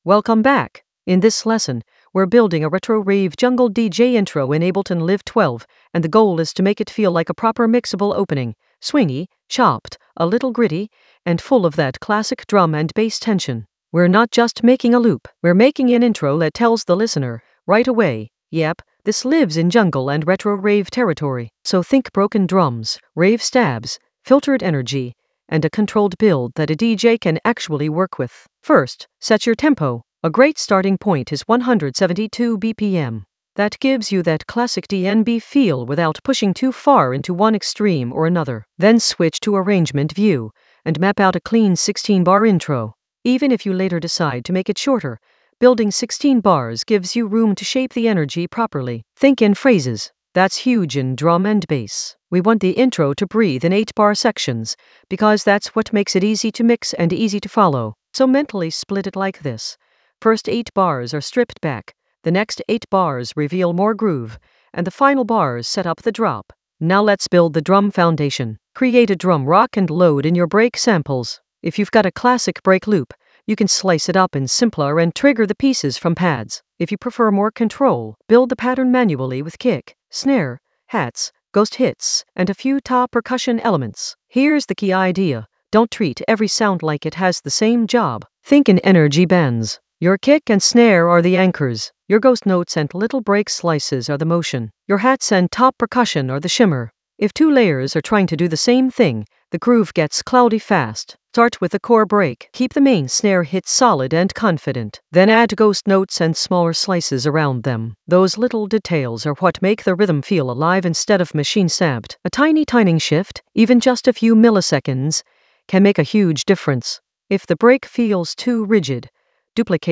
An AI-generated intermediate Ableton lesson focused on Retro Rave jungle DJ intro: swing and arrange in Ableton Live 12 in the Drums area of drum and bass production.
Narrated lesson audio
The voice track includes the tutorial plus extra teacher commentary.